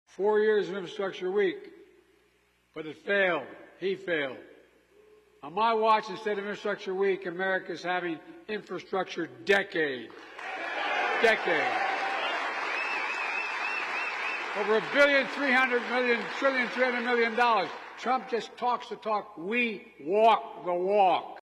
He is increasingly incapable of speaking without mangling words. A speech on infrastructure this week in Las Vegas is an example.